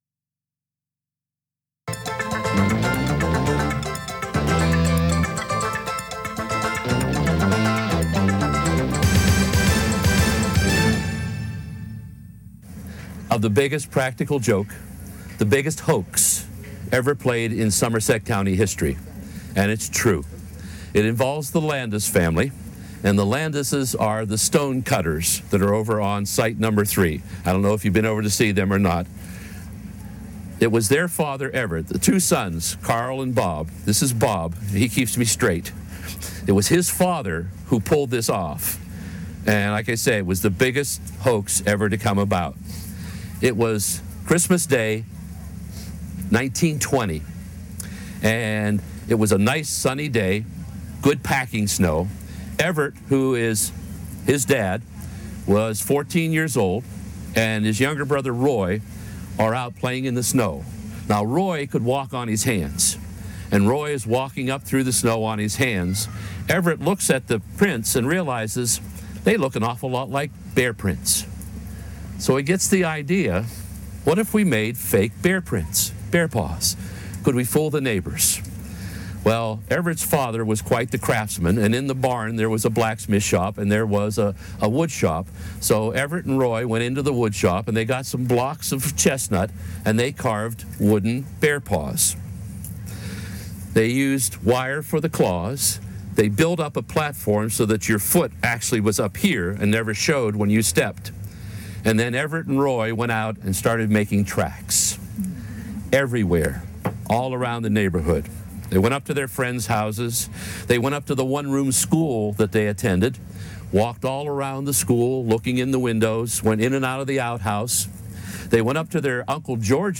at Mountain Craft Days